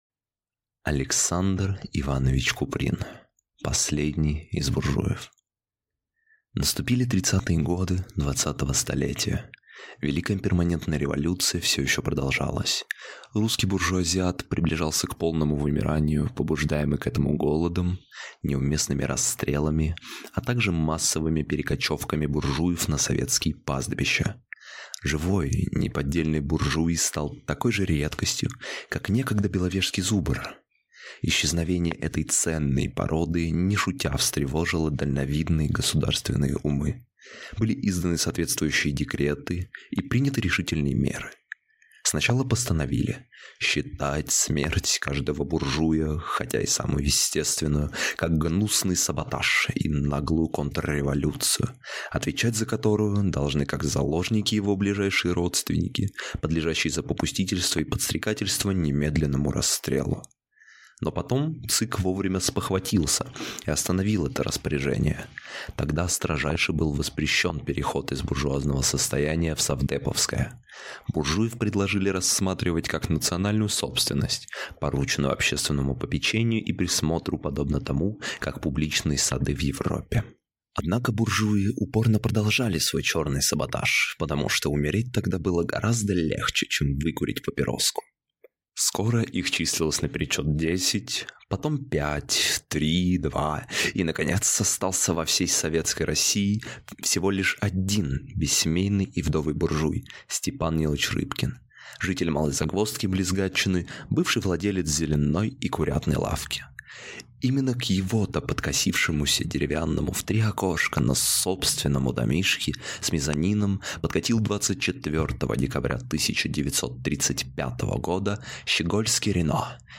Аудиокнига Последний из буржуев | Библиотека аудиокниг